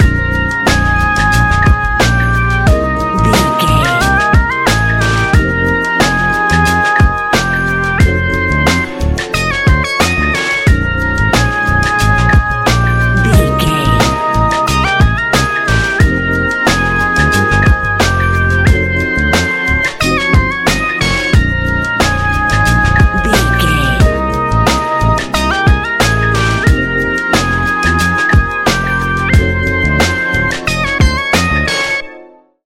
Ionian/Major
A♯
chilled
laid back
Lounge
sparse
new age
chilled electronica
ambient
atmospheric